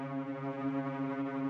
buildup_loop2.wav